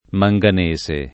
[ ma jg an %S e ]